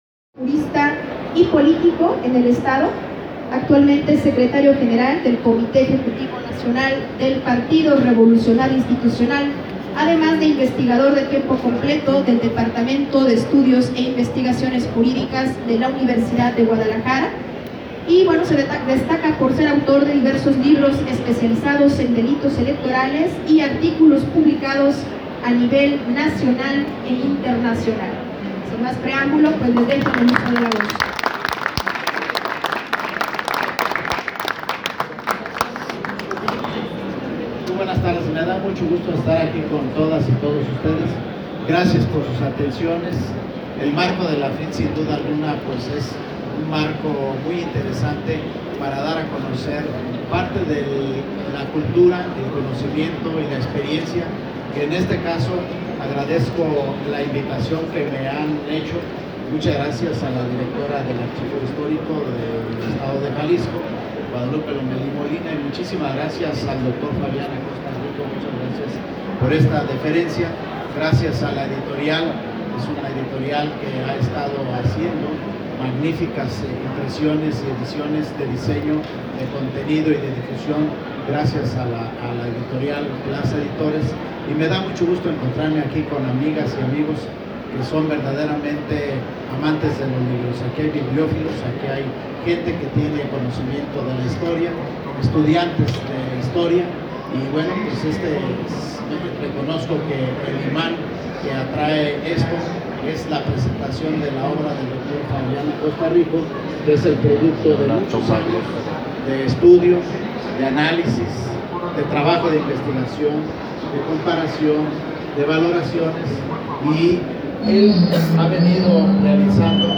Te invitamos a ESCUCHAR el PODCAST de la presentación:
en el Stand de la Editorial de la Universidad de Guadalajara.